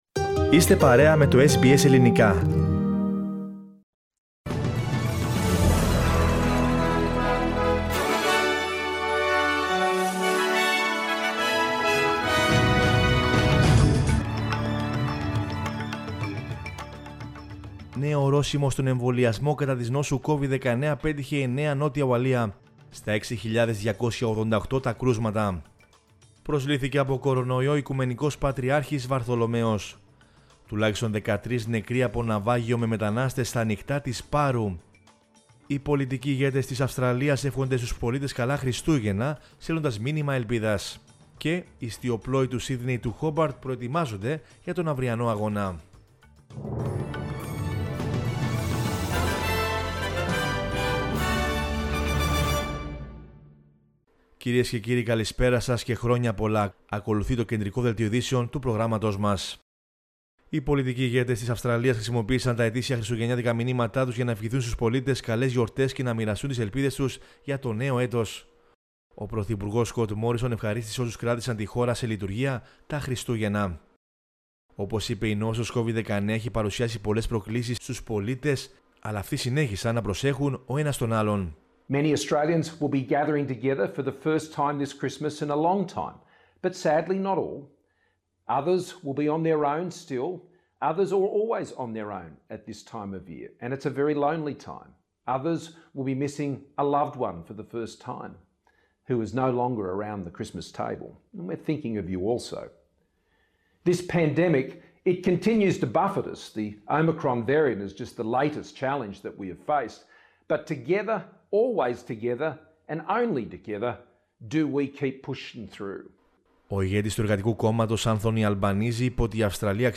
News in Greek from Australia, Greece, Cyprus and the world is the news bulletin of Saturday 25 December 2021.